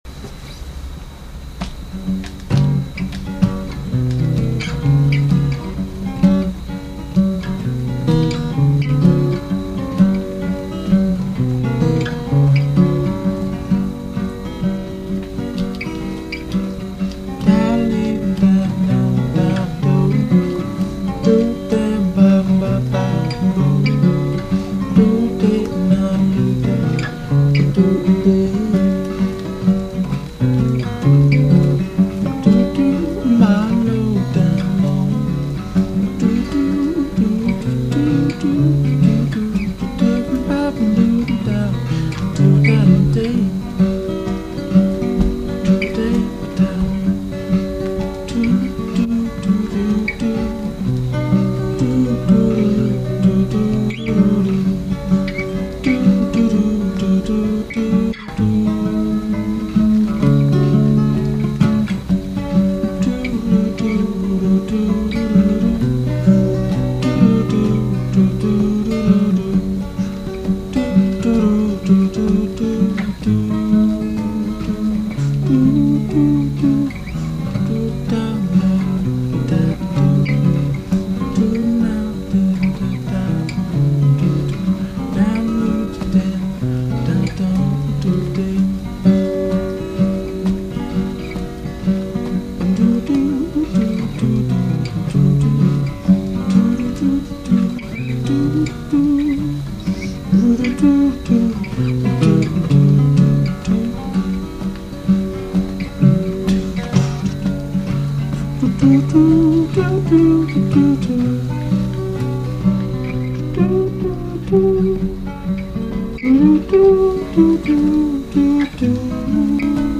Singer- Songwriter.